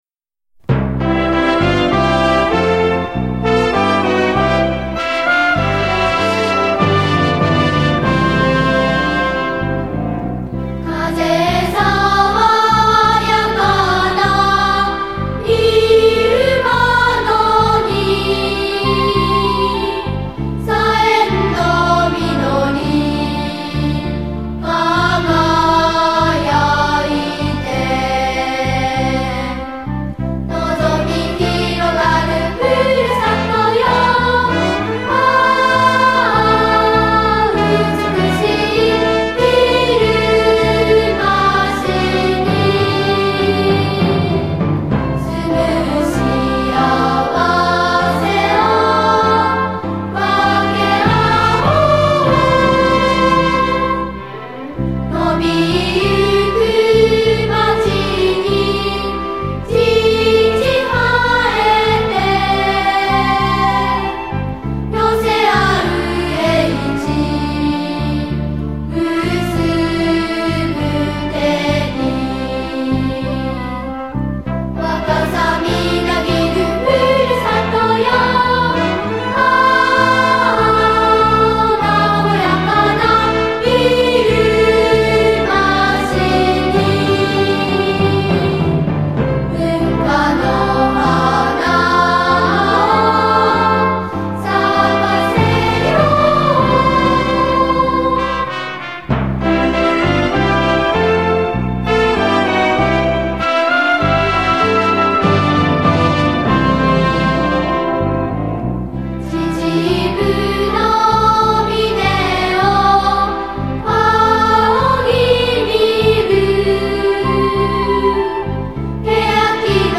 このたび、さらなる普及振興を図るために音源のデジタルリマスターを行い、CDを制作しました。